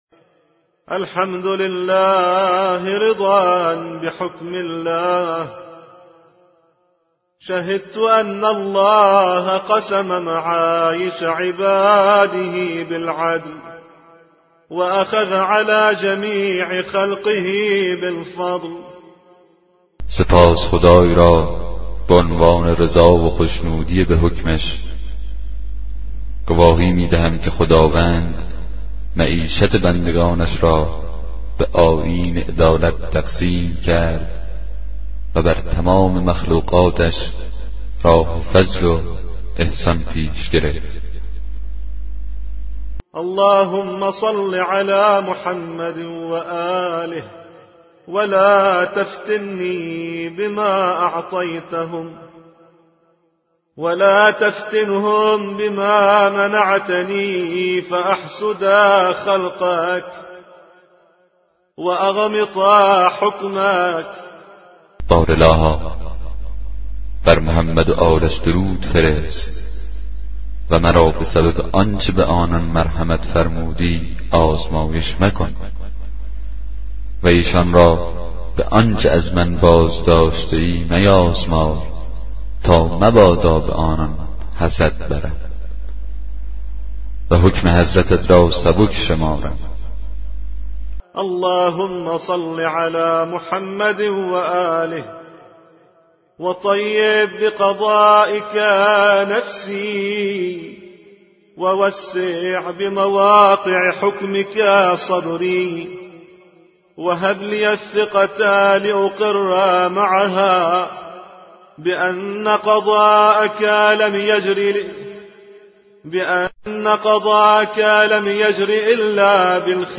کتاب صوتی دعای 35 صحیفه سجادیه